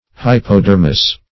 Search Result for " hypodermis" : Wordnet 3.0 NOUN (1) 1. layer of cells that secretes the chitinous cuticle in e.g. arthropods ; The Collaborative International Dictionary of English v.0.48: Hypodermis \Hyp`o*der"mis\, n. [NL.